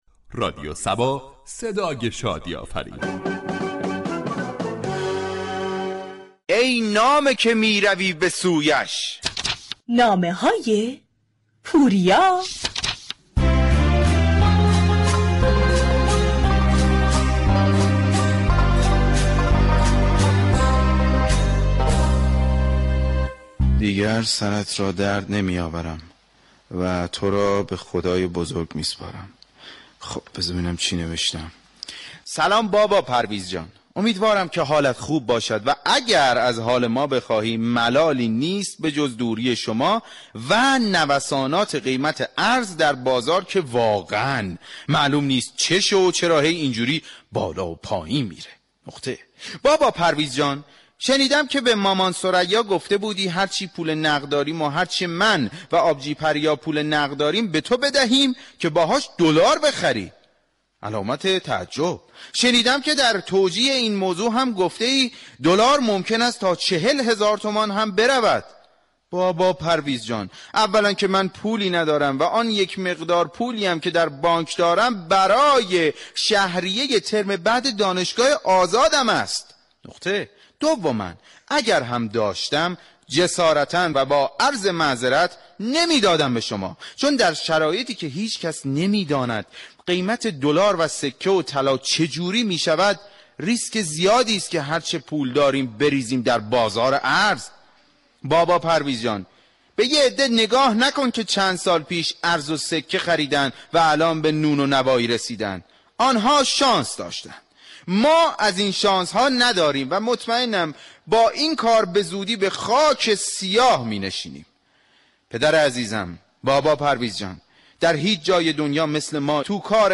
در بخش نمایشی شهر فرنگ با بیان طنز به موضوع نوسانات بازار ارز پرداخته شده است ،در ادامه شنونده این بخش باشید.